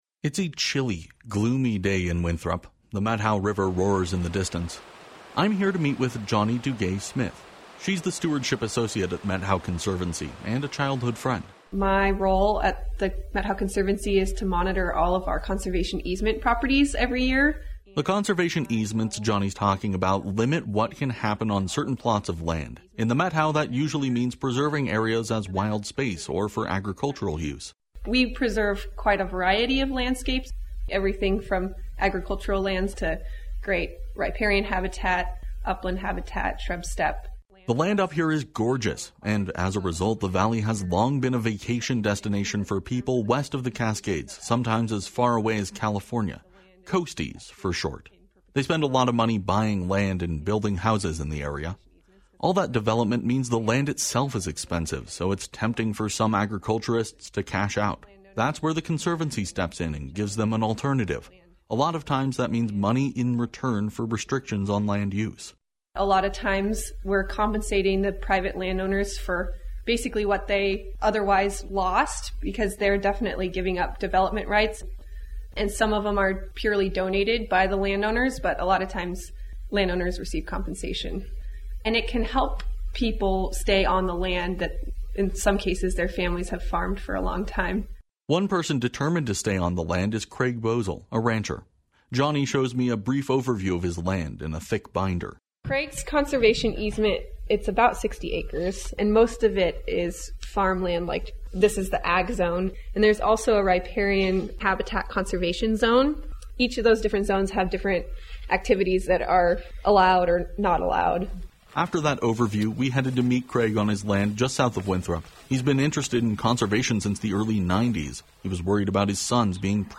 The Methow River roars in the distance.